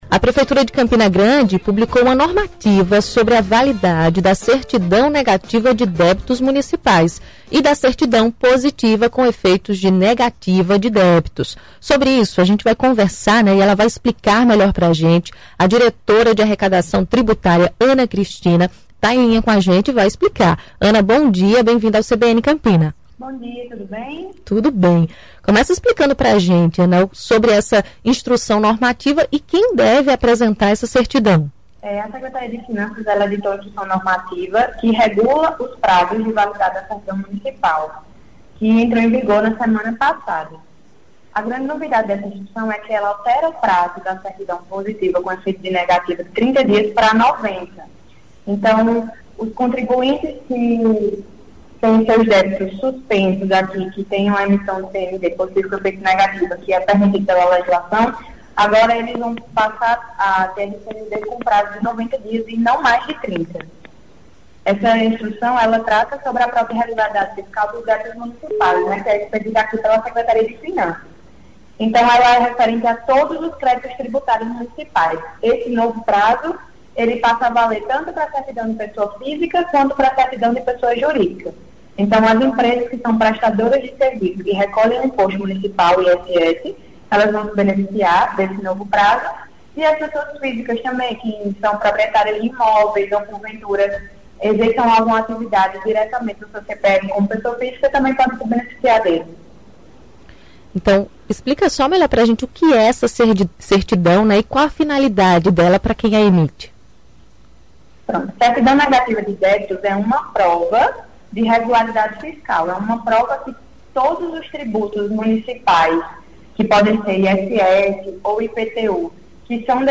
Entrevista: entenda novo prazo de débitos municipais em Campina Grande